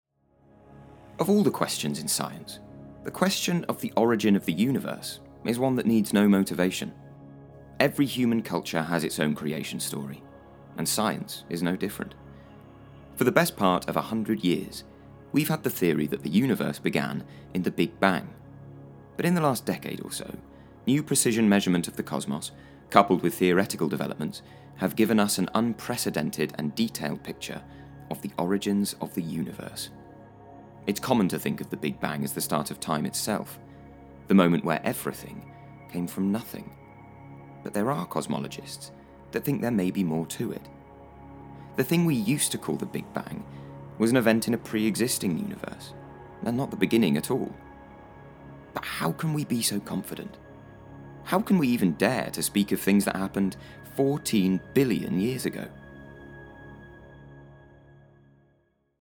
He has a lovely natural, warm and friendly tone to his voice too.
• Male
Showing: Narration & Documentary Clips